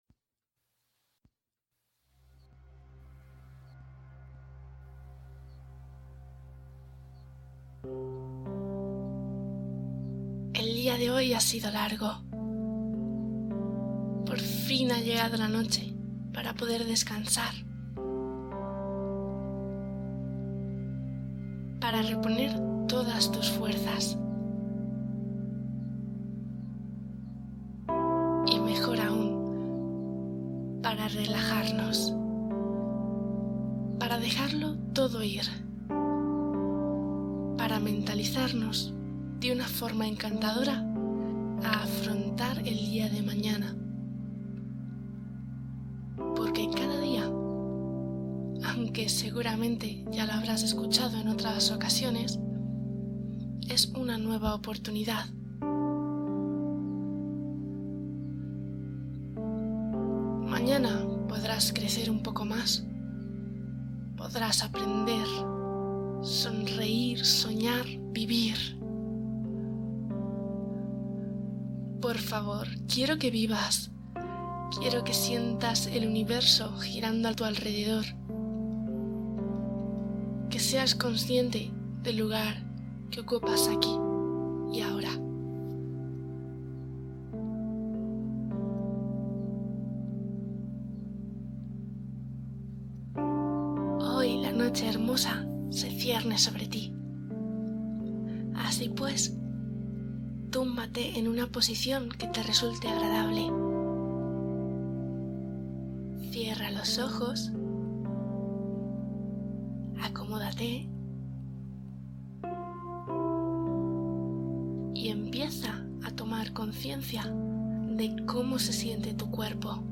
Meditación para dormir profundamente — polvo de estrellas